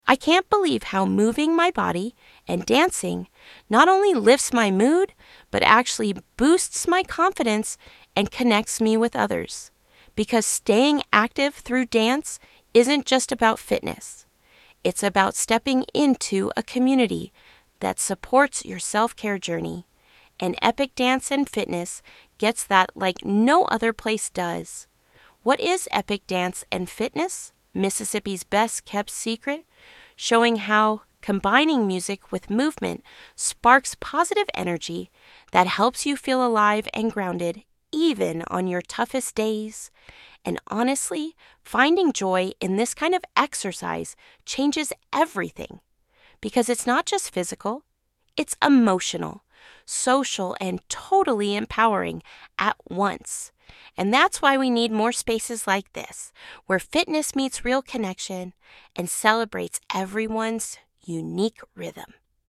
Voiceover video encouraging fitness through dance, connecting self-care with positive energy and community support, featuring upbeat music and minimal text visuals.